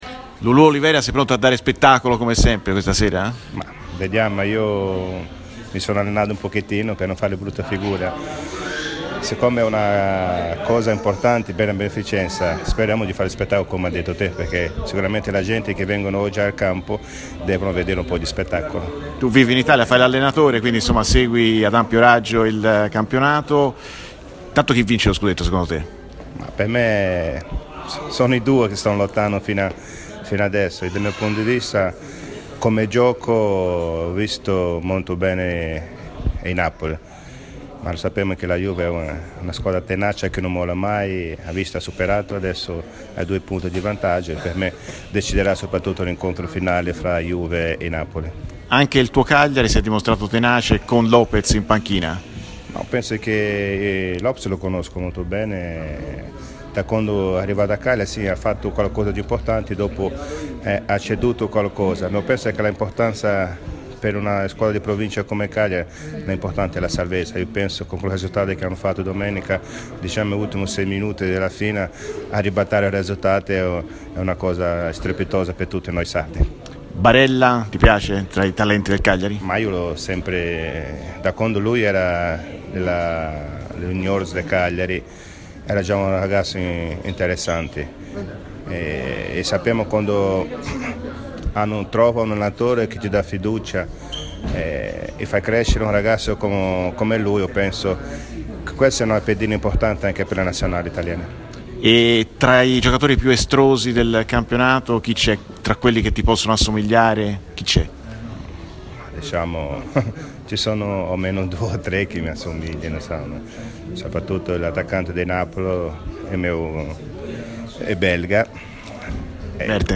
Luis Oliveira, a margine della Partita Mundial, al microfono di RMC Sport © registrazione di TMW Radio